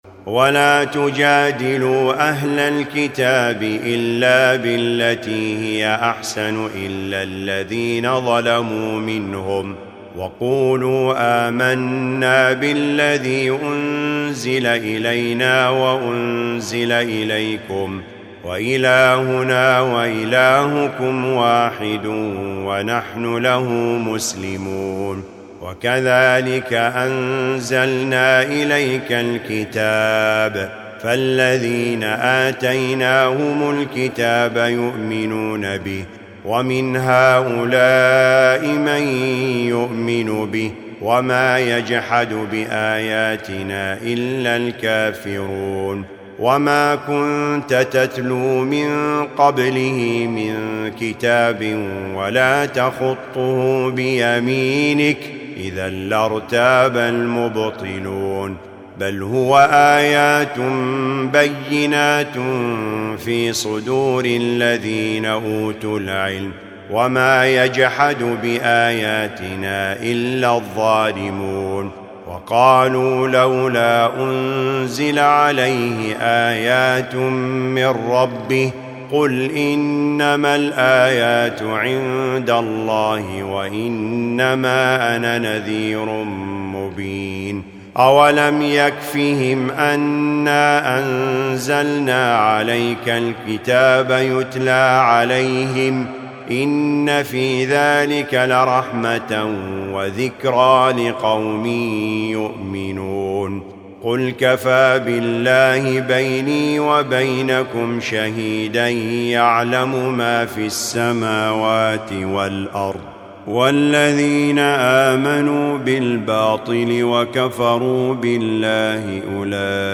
الجزء الواحد و العشرون : العنكبوت 46-69 و الروم و لقمان و السجدة و الاحزاب 1-30 > المصحف المرتل